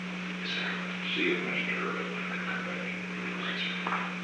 Recording Device: Oval Office
The Oval Office taping system captured this recording, which is known as Conversation 498-004 of the White House Tapes.